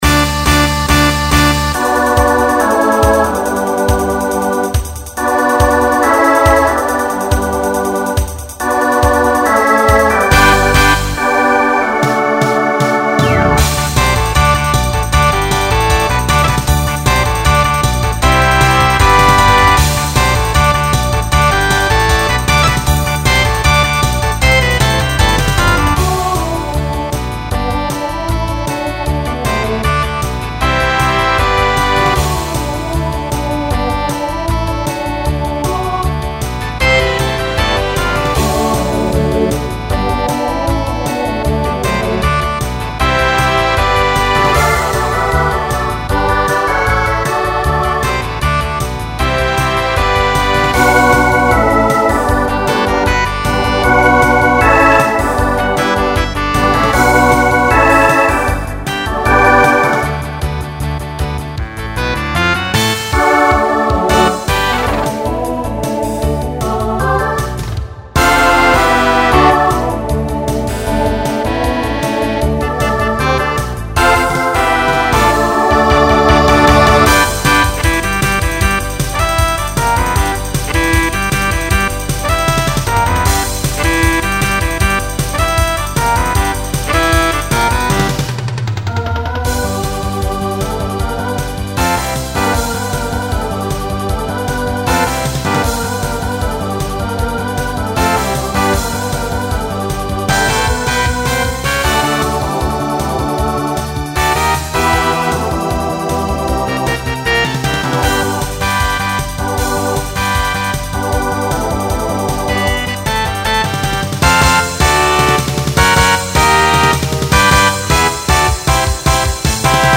Pop/Dance , Rock Instrumental combo
Voicing SATB